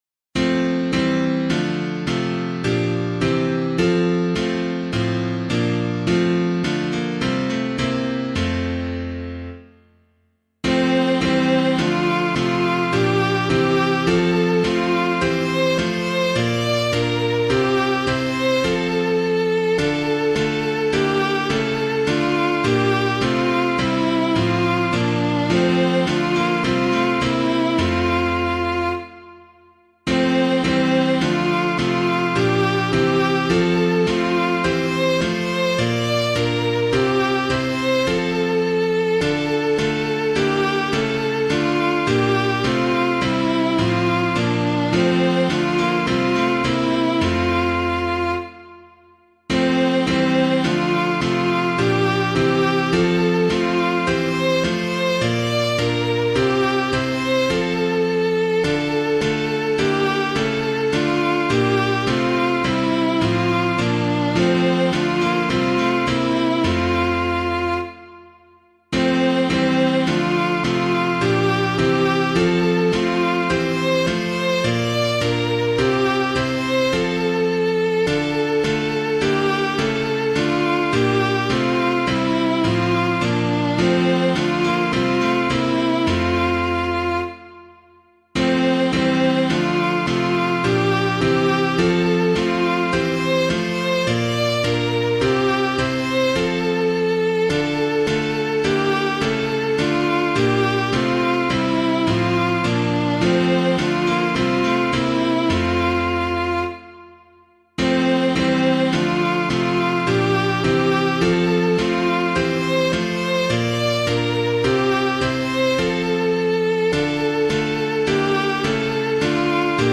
Holy Light on Earth's Horizon [Duggan - STUTTGART] - piano.mp3